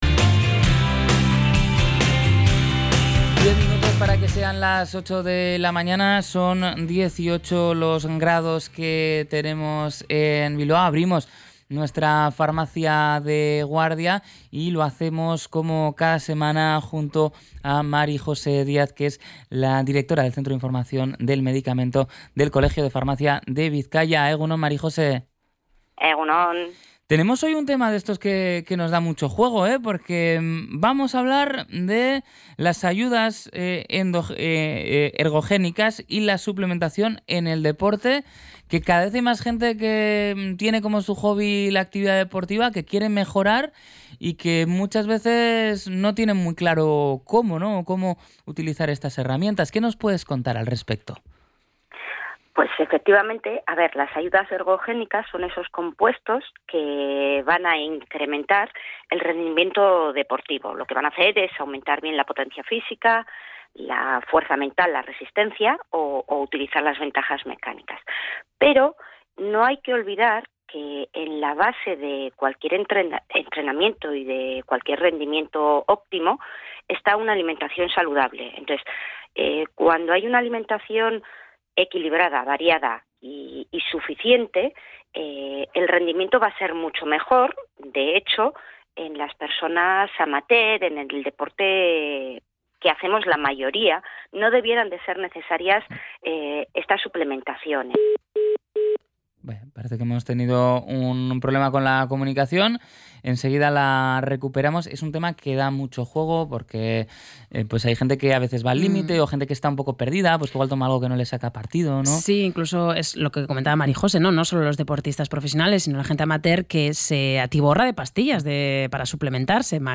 A lo largo de la conversación, ha subrayado que una alimentación equilibrada es la base de cualquier buen rendimiento y que, en deporte aficionado, no necesitamos recurrir a suplementos.